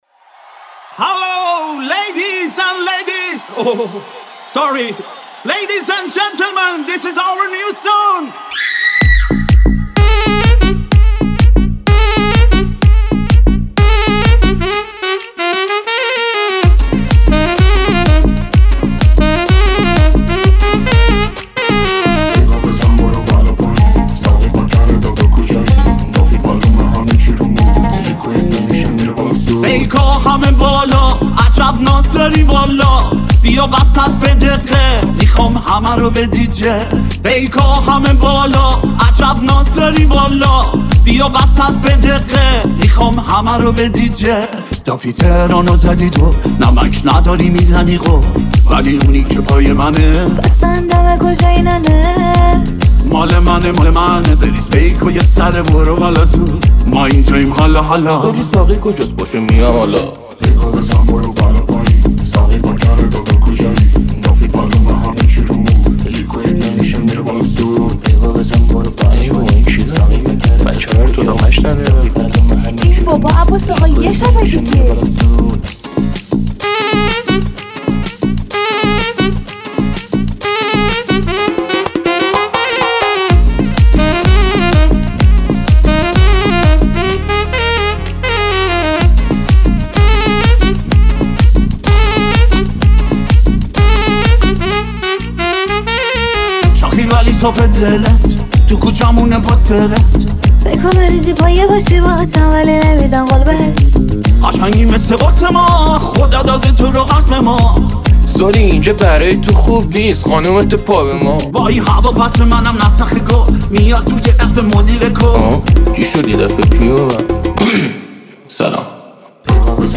آهنگ پاپ ایرانی